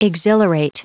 Prononciation du mot : exhilarate
exhilarate.wav